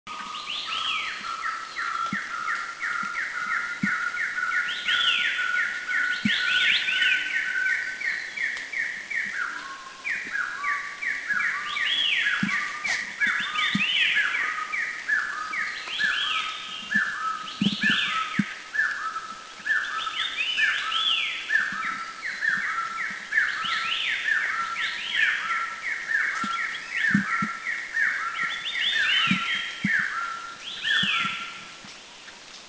Taiwan Partridge Arborophila crudigularis Near-threatened (NT) Country endemic
B2A_TaiwanPartridgeBedongyueshan410_SDW.mp3